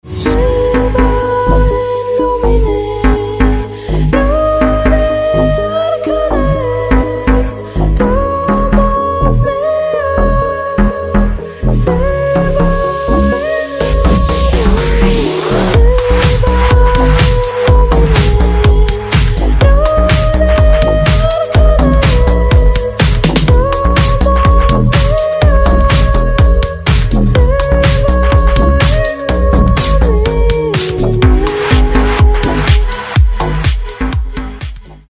deep house
красивый женский голос
красивый женский вокал
house
Красивая музыка с чарующим женским вокалом.